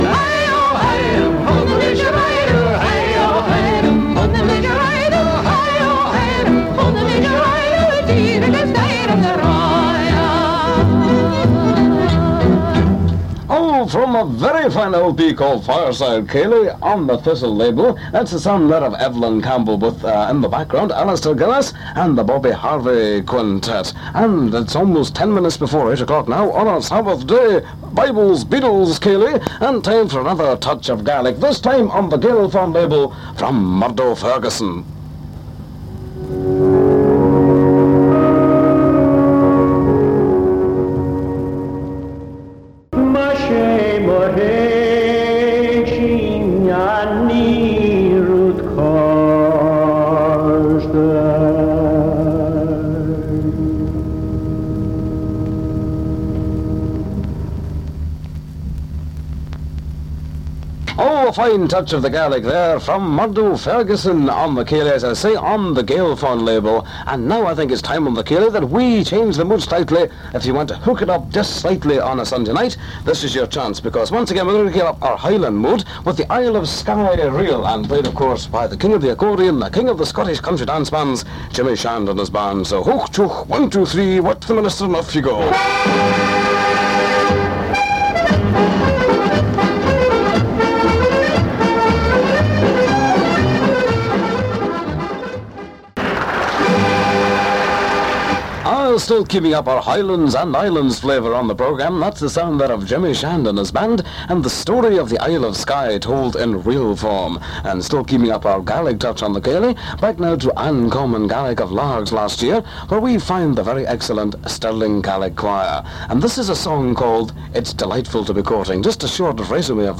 The recordings were made on a couple of Sundays in July 1966, using an Alba receiver and a Ferrograph 422U tape recorder.